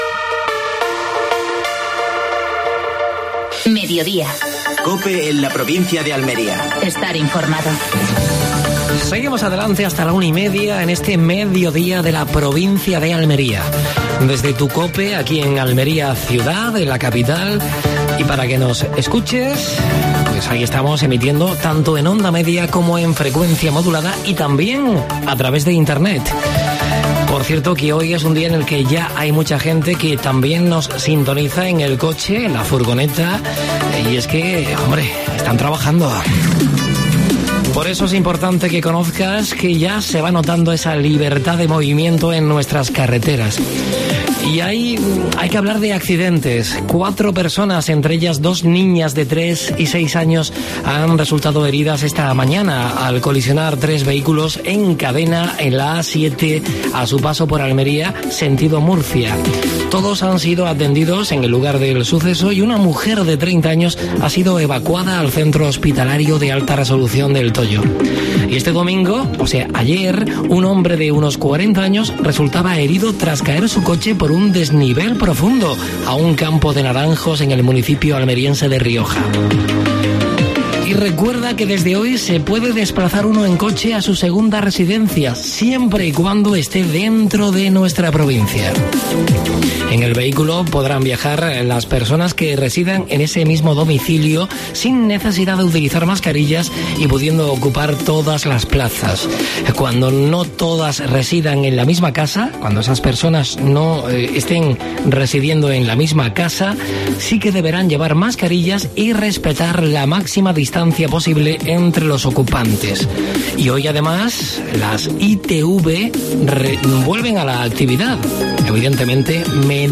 AUDIO: Entrevistamos a la diputada de Bienestar Social de Almería, Ángeles Martínez, sobre el Programa 'Contigo'.